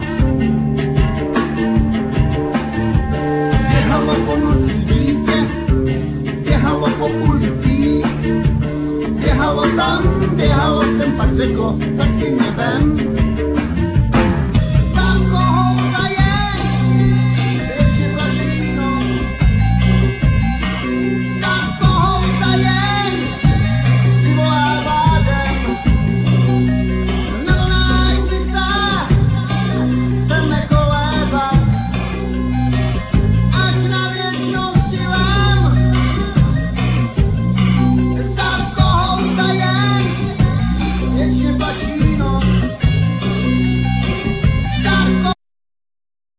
Guitar,Vocal
Bass
Drums,Percussion,Sound Wheel
Trumpet
Keyboards
Children Choir